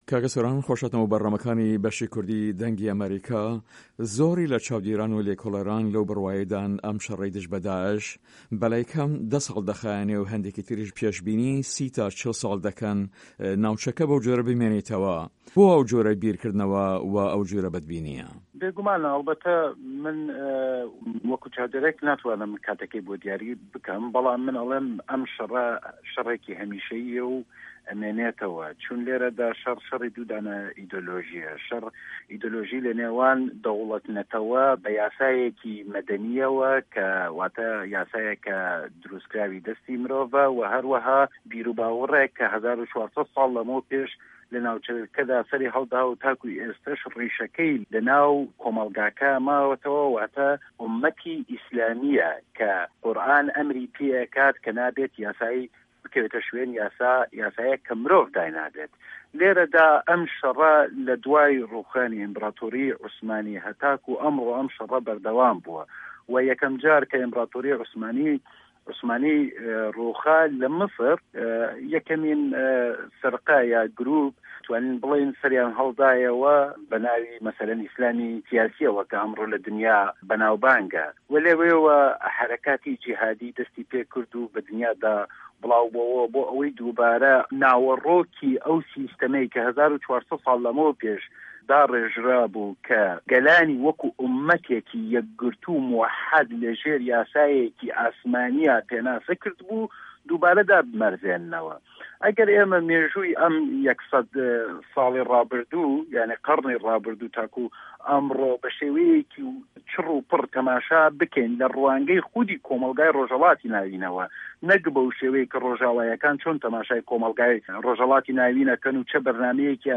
له‌ هه‌ڤپه‌یڤینێکدا له‌گه‌ڵ به‌شی کوردی ده‌نگی ئه‌مه‌ریکا